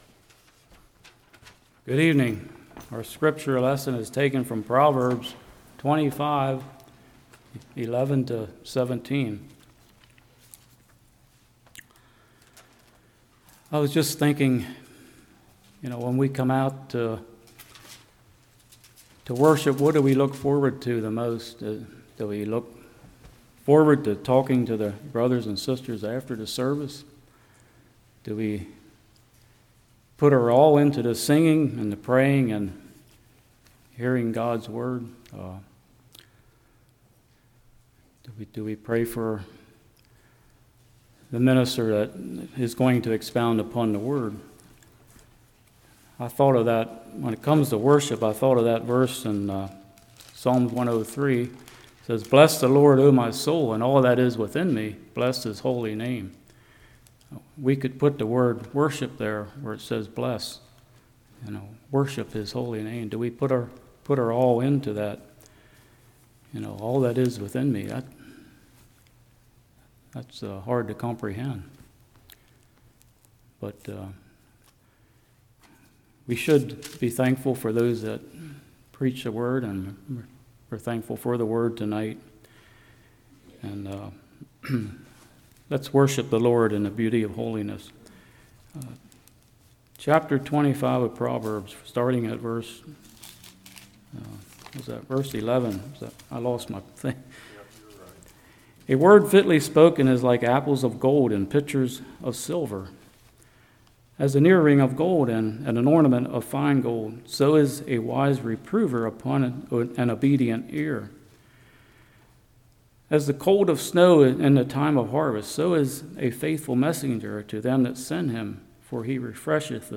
Proverbs 25:11-17 Service Type: Evening Who are your friends?